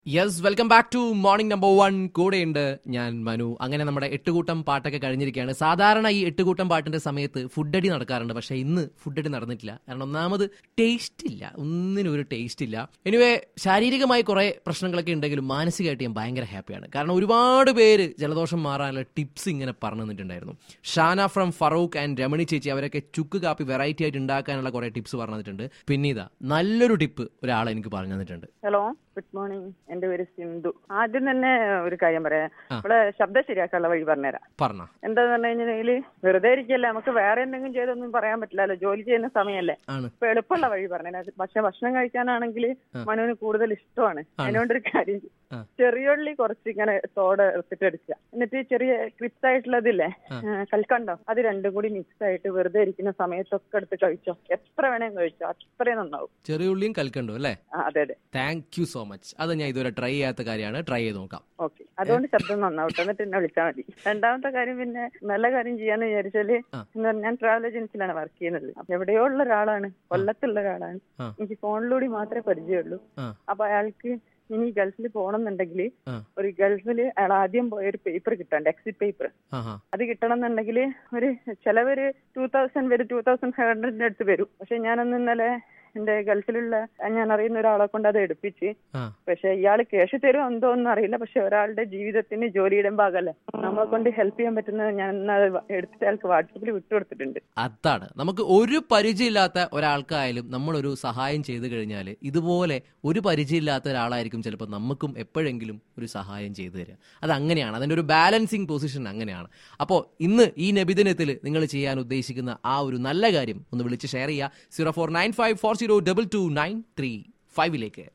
MEDICAL TIPS FROM A LISTENER